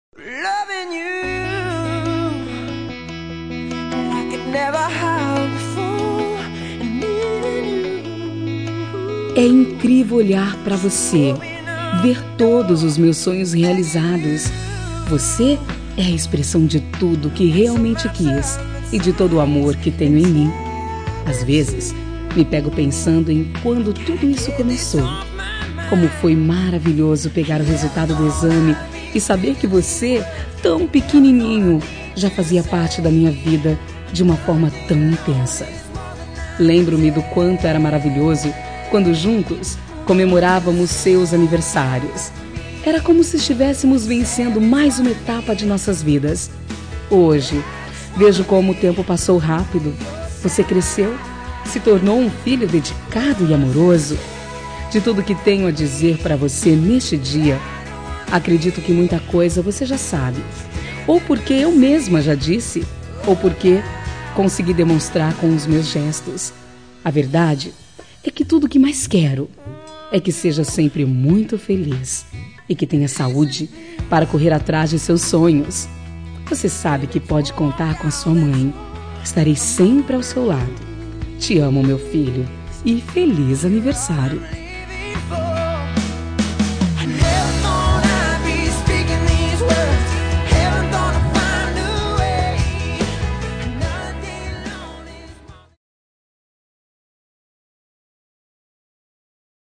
Telemensagem de Aniversário de Filho – Voz Feminina – Cód: 1822